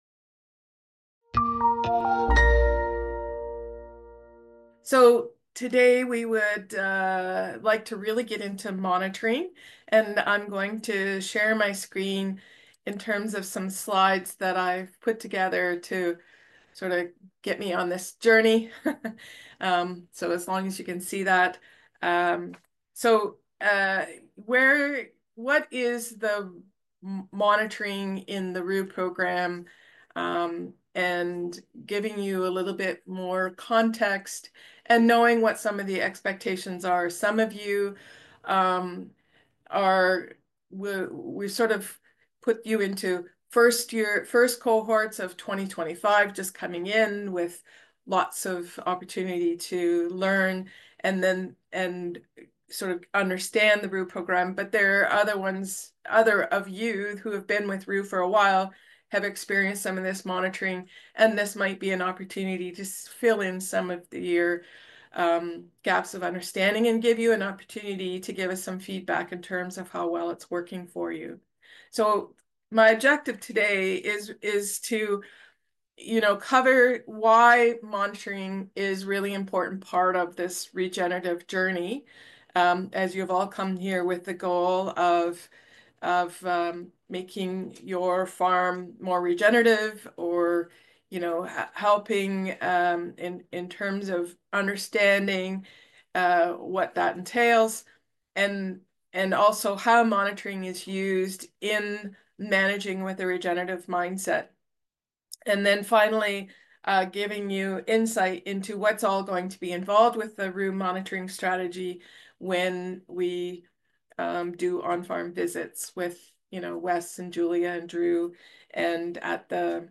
Here is the slide deck used in the panel discussion on “Importance of Monitoring”.